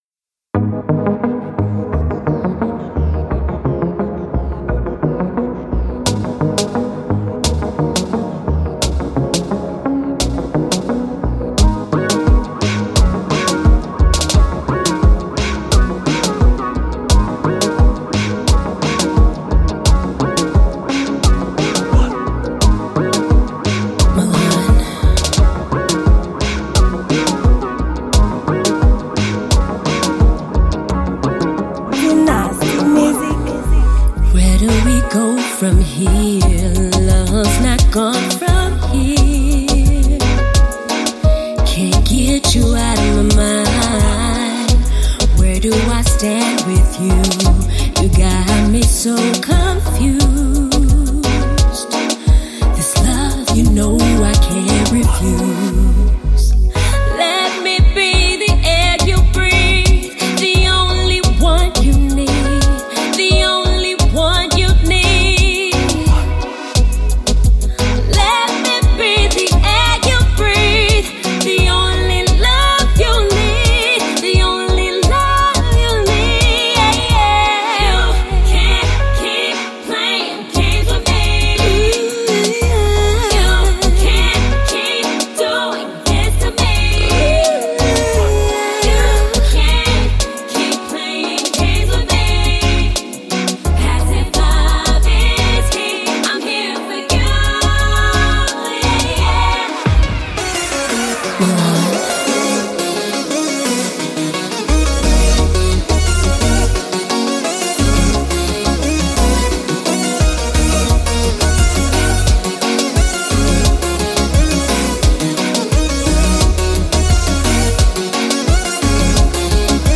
Genre: Zouk.